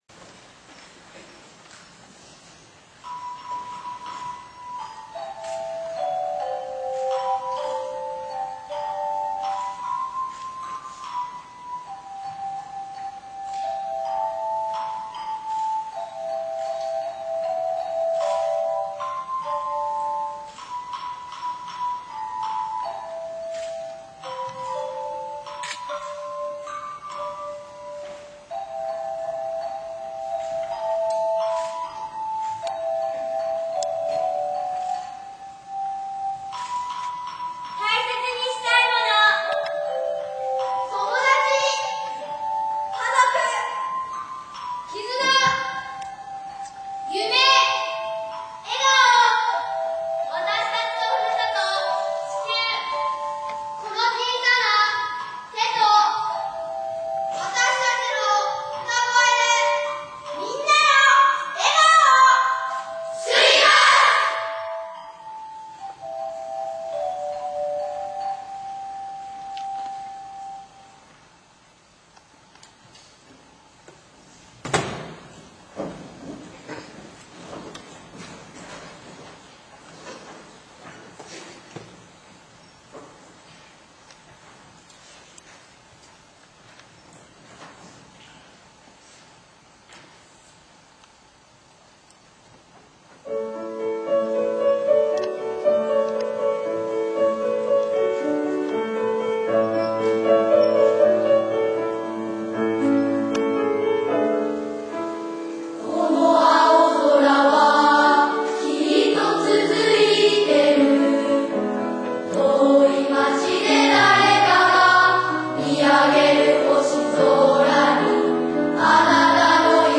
坂井市音楽会（５・６年生)
２年に１度参加している坂井市音楽会に５・６年生が参加しました。曲名は、「地球星歌〜笑顔のために〜」です。この日のために毎日練習を続けてきだだけあって、心を一つに歌い上げた歌声は、聞いてる人の心を大きく揺さぶるほどの完成度の高さです。
ぜひこの素晴らしい歌声を